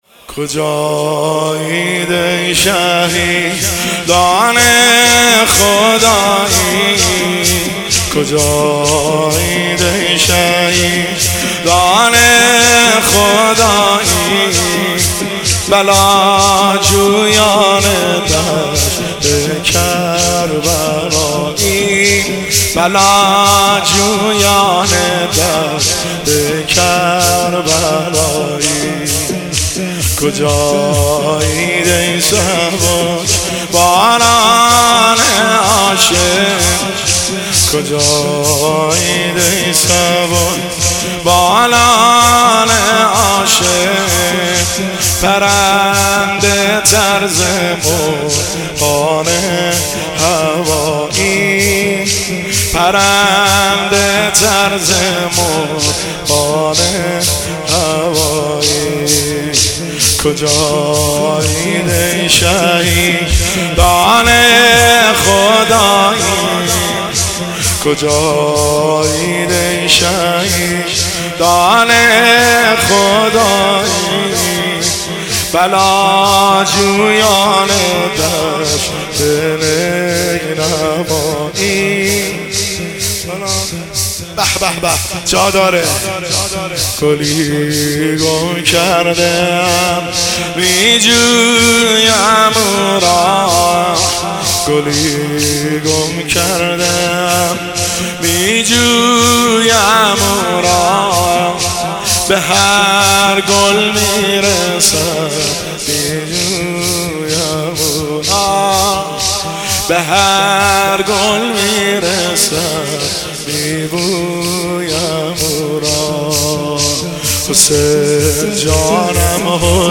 پیش زمینه – شب اول فاطمیه اول 1398